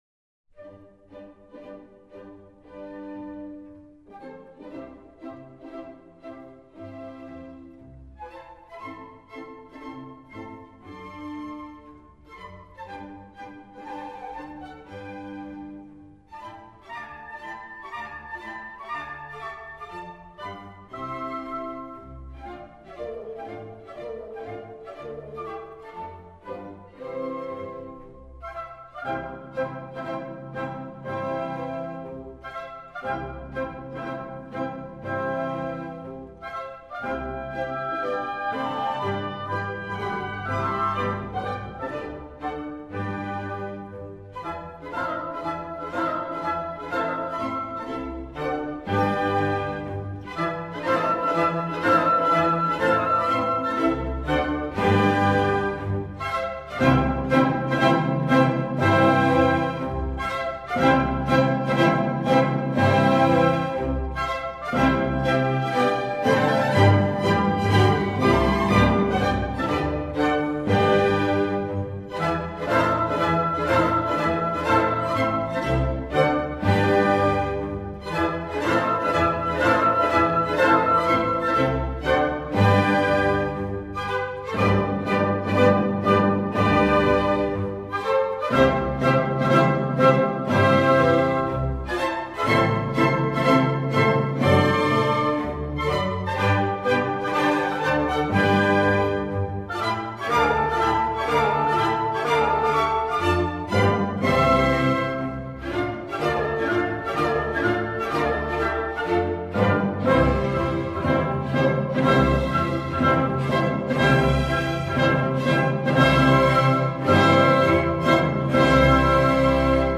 A05-13 Mozart, The Marriage of Figaro, March | Miles Christi